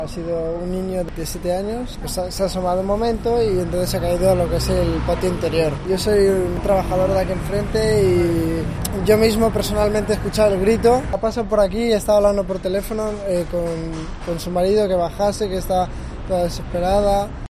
Un testigo, trabajador de un taller enfrente del edificio donde han sucedido los hechos